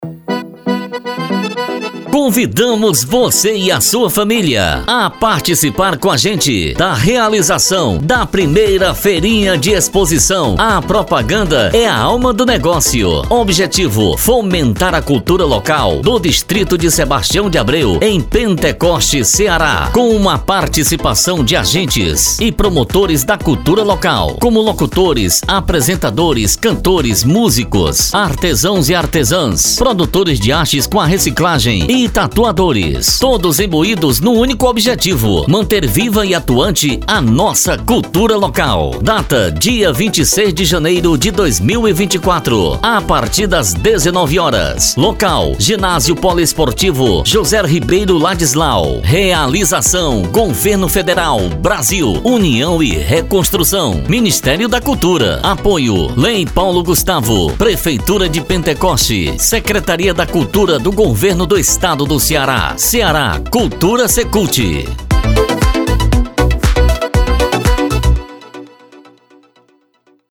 spot-do-carro-volante.mp3